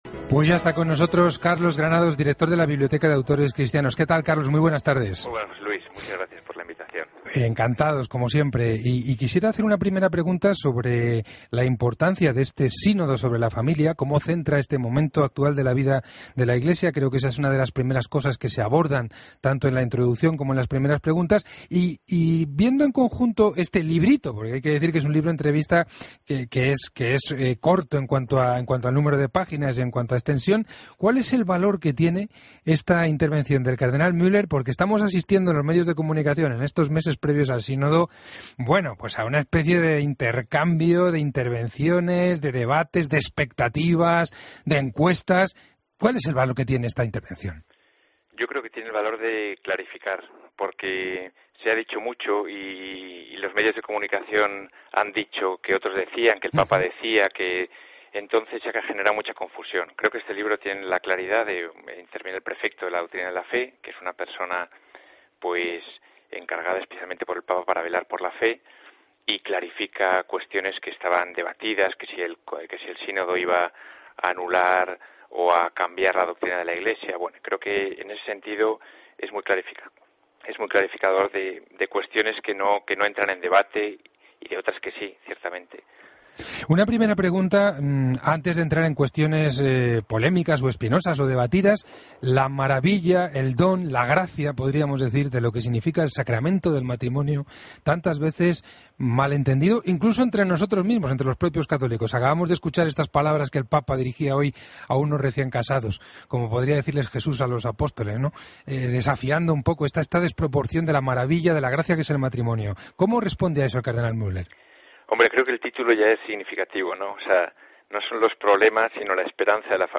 Entrevista en El Espejo